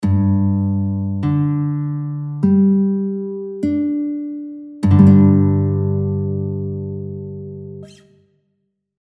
Стрій Соль (G),
Акорд давнього строю – соль-ре-соль-ре, GDGD (mp3):
Bandurka_G-strij_DAVNIJ_Akord_GDGD.mp3